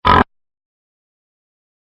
Cat Meow
Cat Meow is a free animals sound effect available for download in MP3 format.
340_cat_meow.mp3